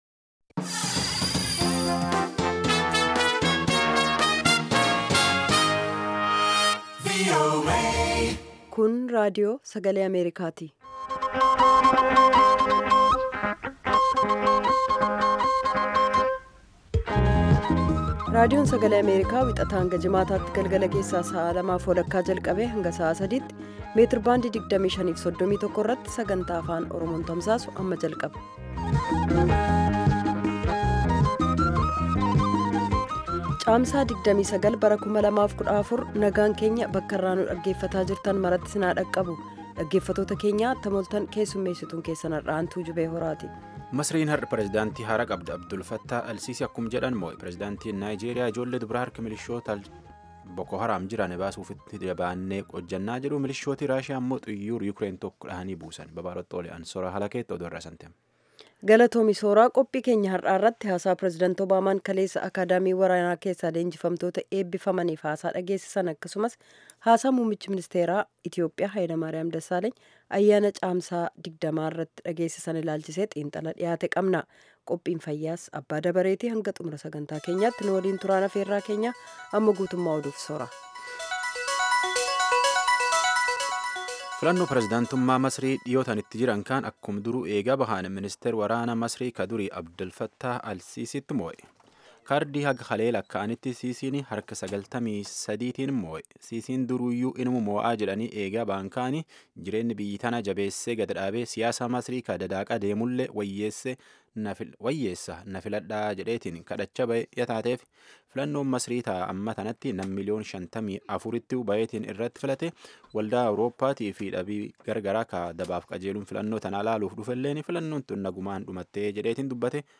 Half-hour broadcasts in Afaan Oromoo of news, interviews with newsmakers, features about culture, health, youth, politics, agriculture, development and sports on Monday through Friday evenings at 8:30 in Ethiopia and Eritrea.